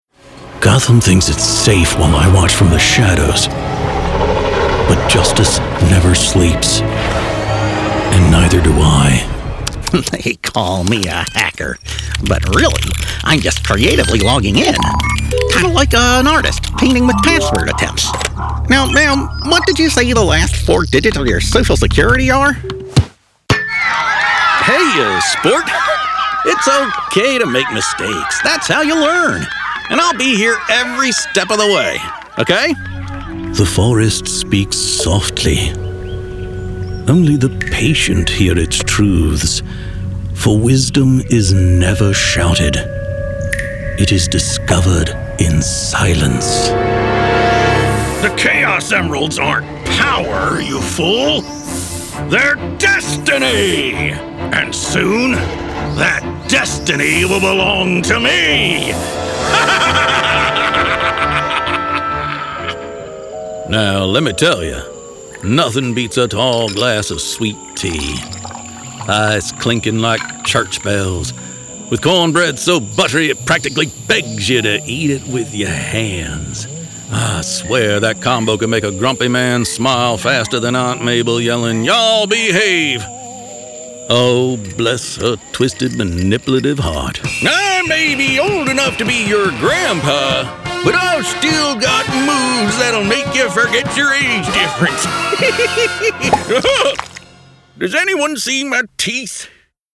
0918Animation_VO_Demo_audio.mp3